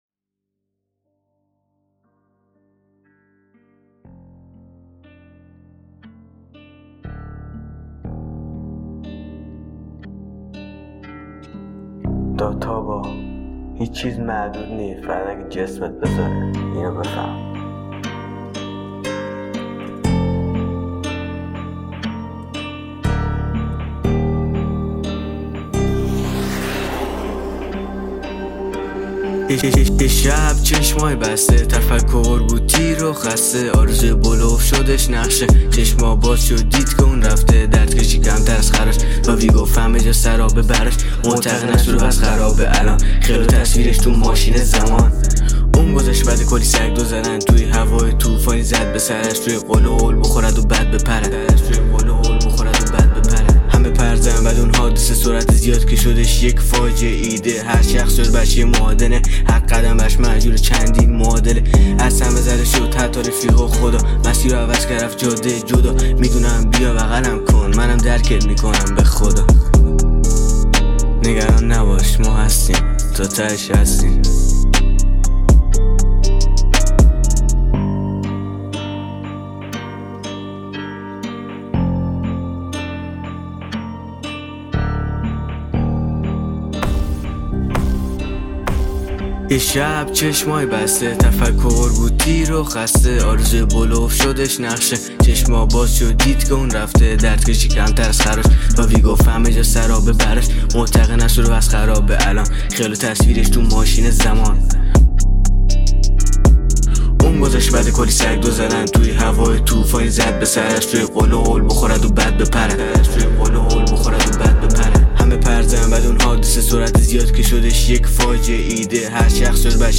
رپر